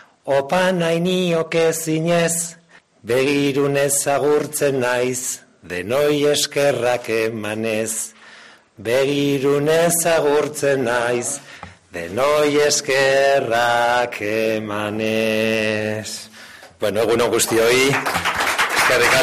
Urtaran se despide como alcalde de Vitoria con unos bertsos cantados en euskera
El alcalde de Vitoria, Gorka Urtaran, se ha arrancado a cantar unos bertsos en euskera como anticipo de su despedida del Ayuntamiento. Nada más comenzar el acto de balance de sus dos legislaturas ha dedicado casi un minuto a un recital que ha terminado con el coro de algún edil jeltzale y el aplauso de todos ellos.